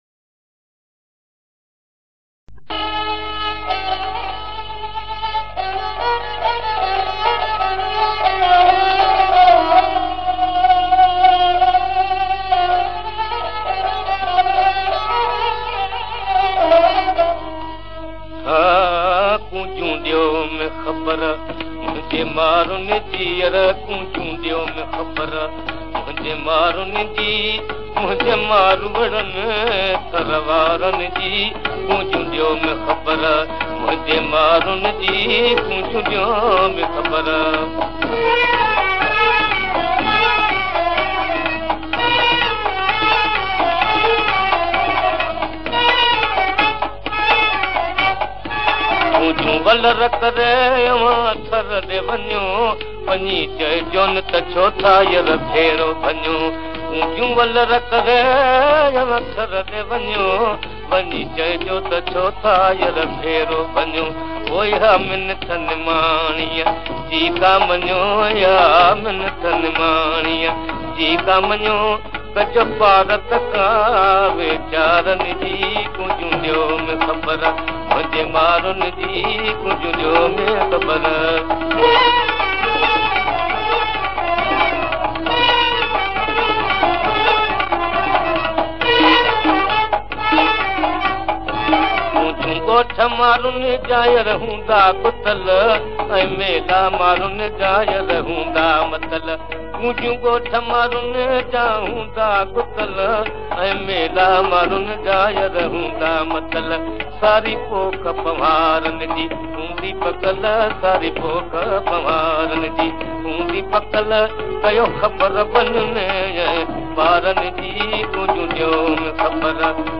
Classic Sindhi Songs